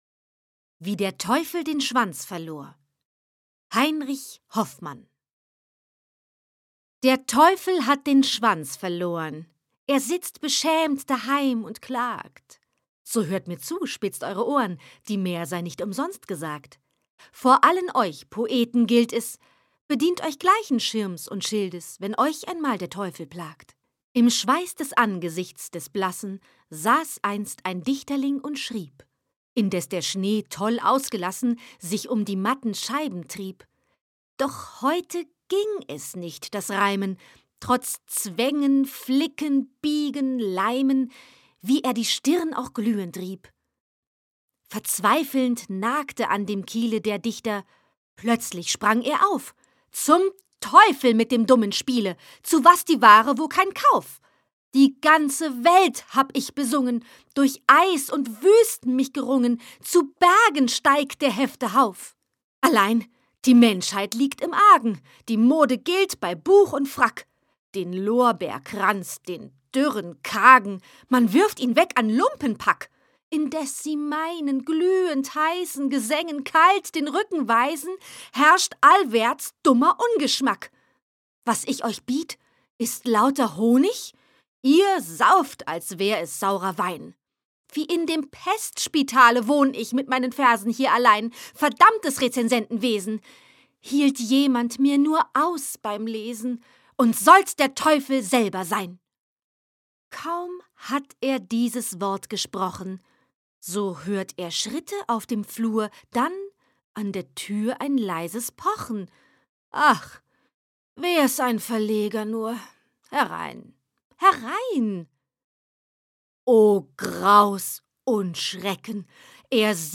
Lyrik